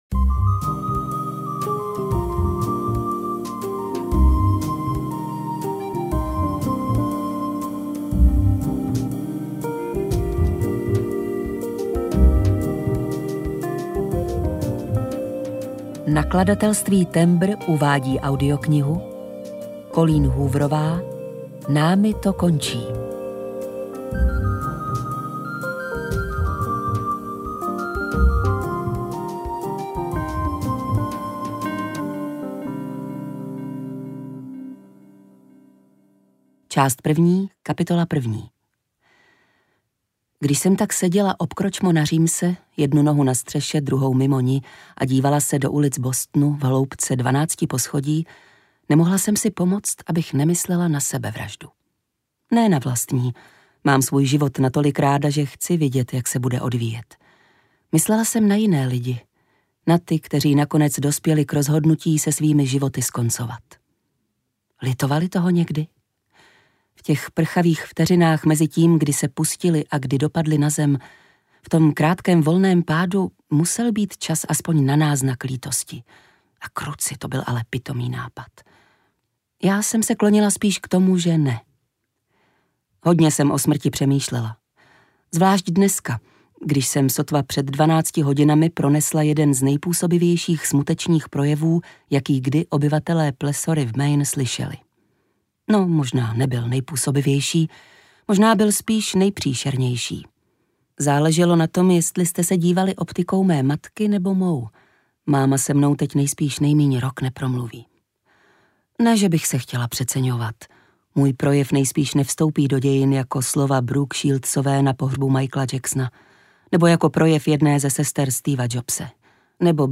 Námi to končí audiokniha
Ukázka z knihy
• InterpretDana Černá